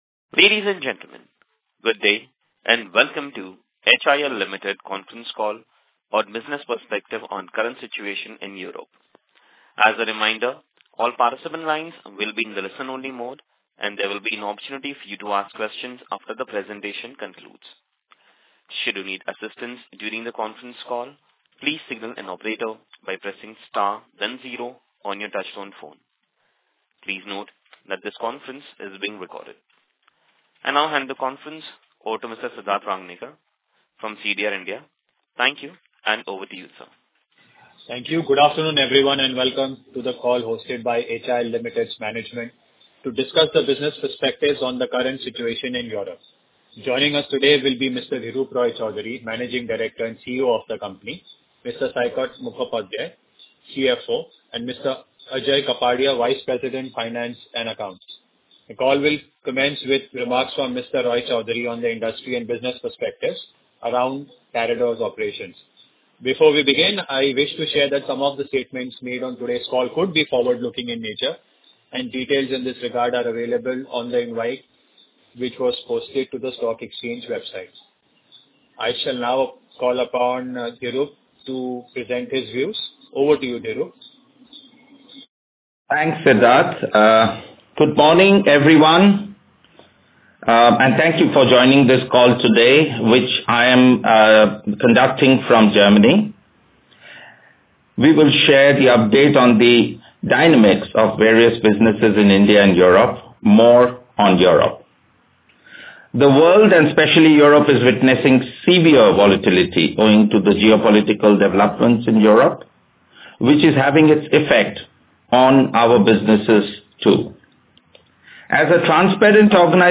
Investors call dated March 14, 2024